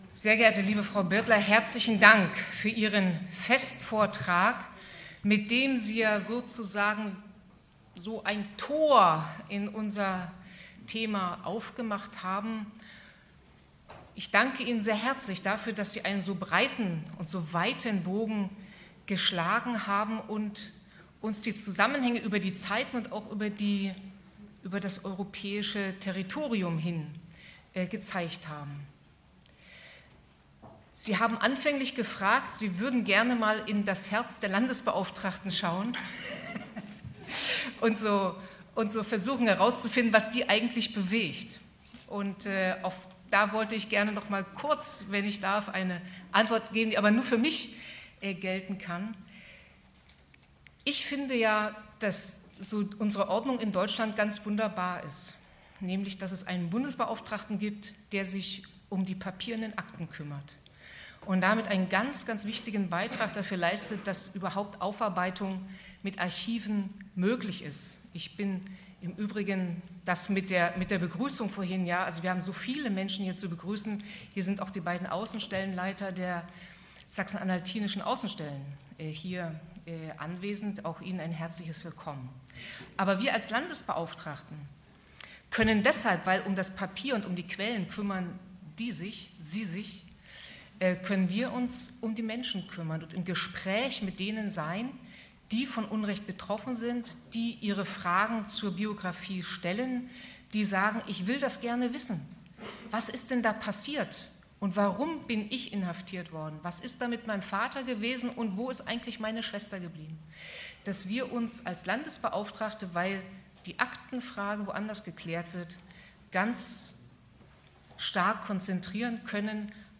Audiodokumentation Bundeskongress 2017: „Erinnern und Zeichen setzen! – Zeugnisse politischer Verfolgung und ihre Botschaft.“ 28./29./30. April 2017, Magdeburg, Maritim Hotel (Teil 1: 28. April)
Abmoderation Birgit Neumann-Becker